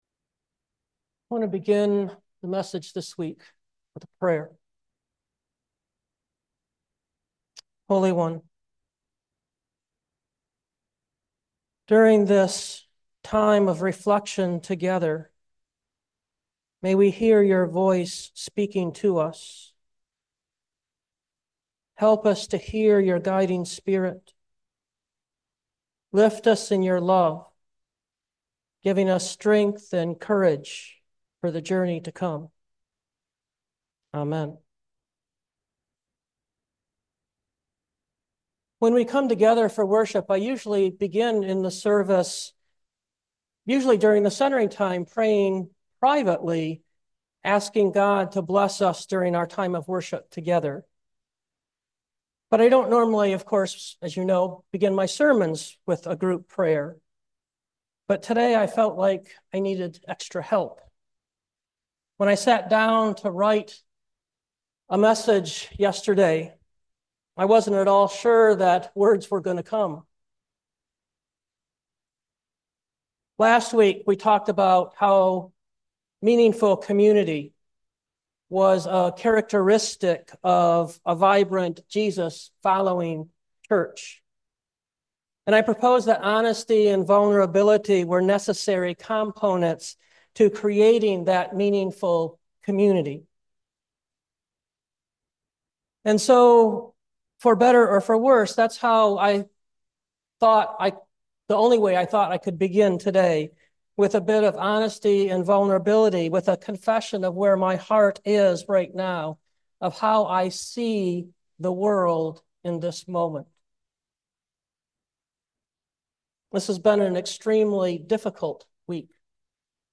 2024 Witnesses of Love Preacher